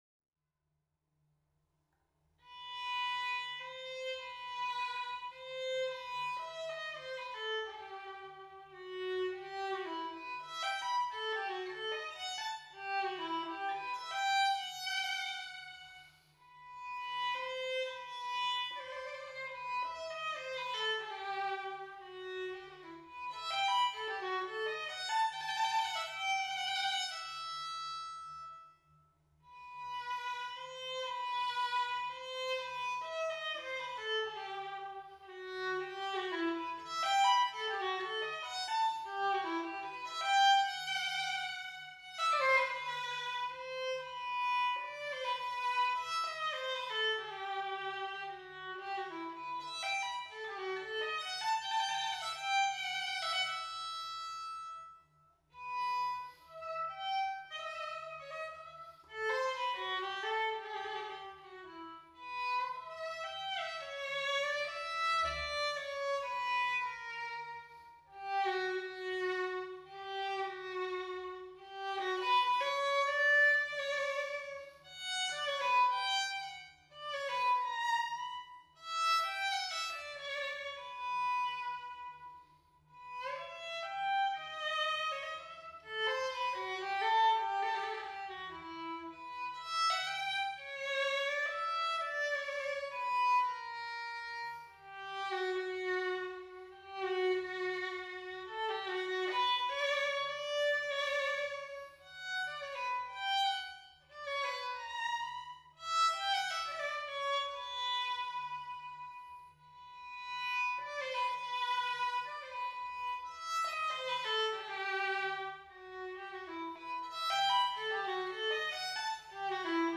Workshop recording (at the desk) 9 am 25 2 16
Coffee, something ancient, roses, and beautiful bit of anonymous 18th Century fiddle music 25 2 16
I found this beautiful pair of arias for solo violin inscriber at the back of a Liege edition of Carlo Tessarini’s ‘Nouvelle méthode pour apprendre par théorie dans un mois de temps à jouer le violon, divisé en trois classes, avec des leçons à deux violons par gradation’ (Liège, 1750)